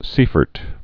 (sēfərt, sī-)